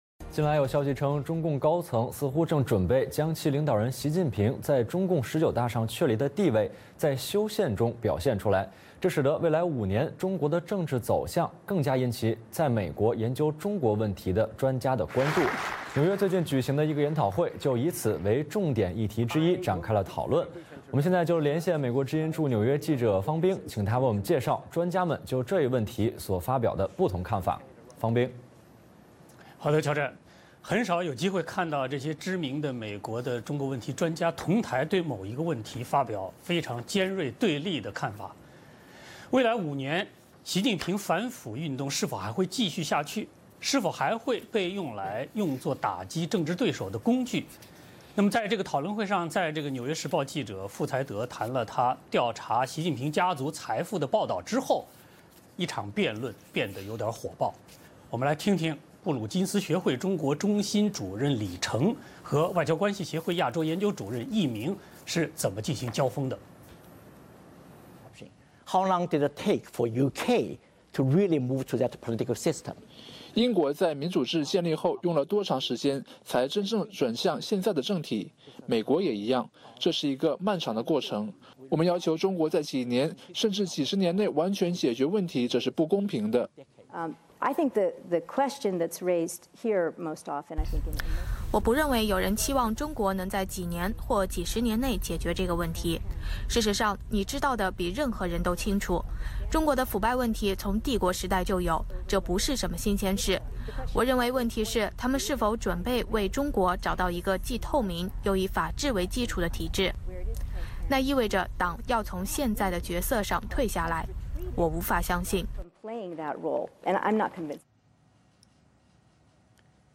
未来五年中国领导人的走向是星期三纽约举行的一场研讨会的首场讨论的主题。